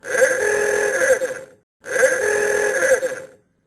[SOUND] Nerd Car Horn.ogg